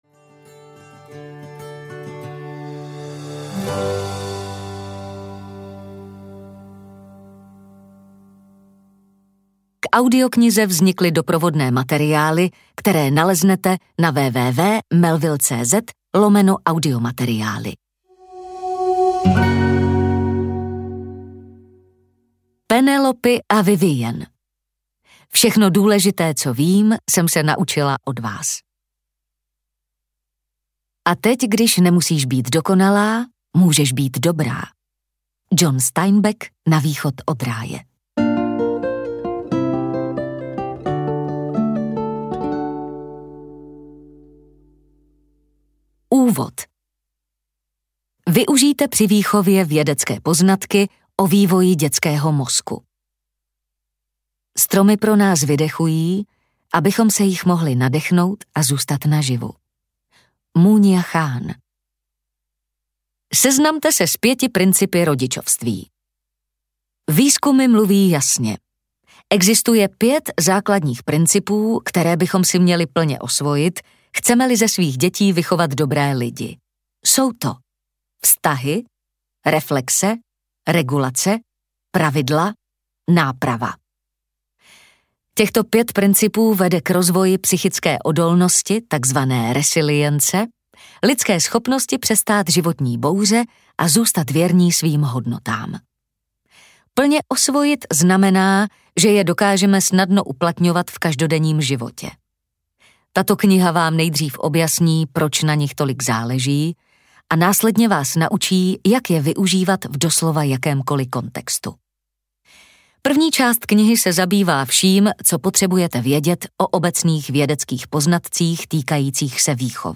5 principů rodičovství audiokniha
Ukázka z knihy